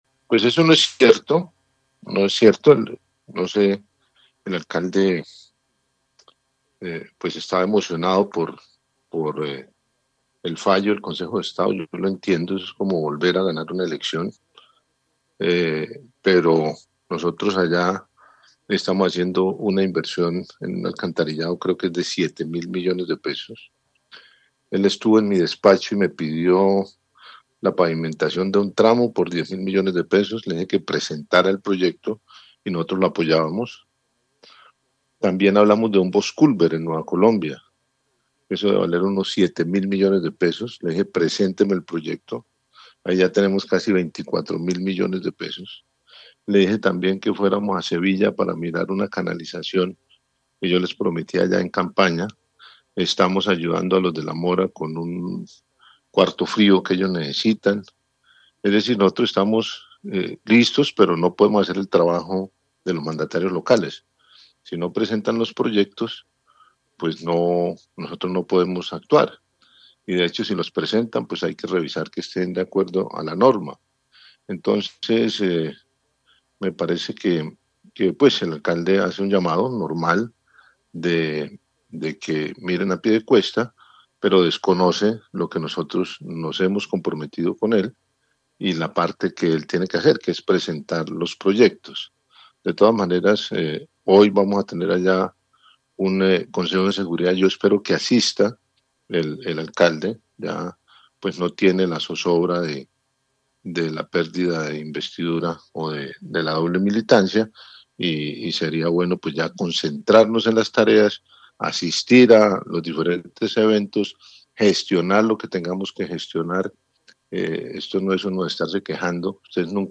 Juvenal Diaz, gobernador de Santander le responde a las críticas del alcalde de Piedecuesta